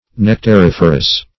nectariferous - definition of nectariferous - synonyms, pronunciation, spelling from Free Dictionary
Nectariferous \Nec`tar*if"er*ous\, a.
nectariferous.mp3